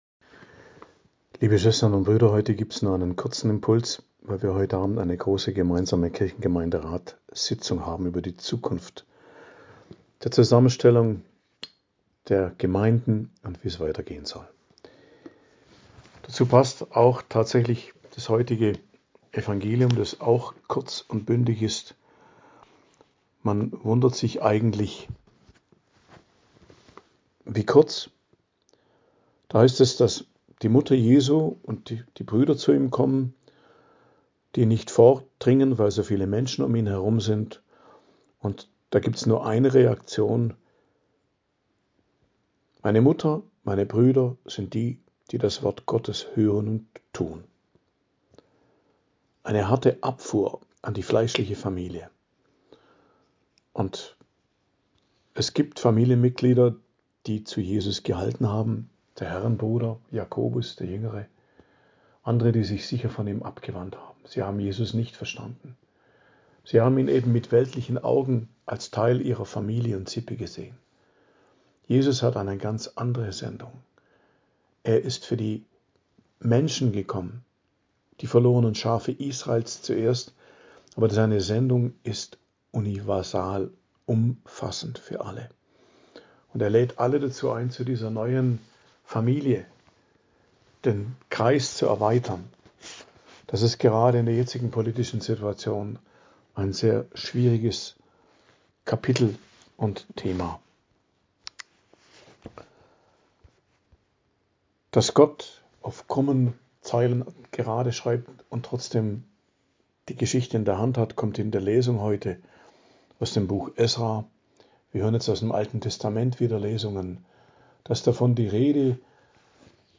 Predigt am Dienstag der 25. Woche i.J., 23.09.2025